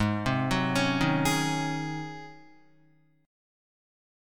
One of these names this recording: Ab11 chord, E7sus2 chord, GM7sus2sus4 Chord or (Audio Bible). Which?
Ab11 chord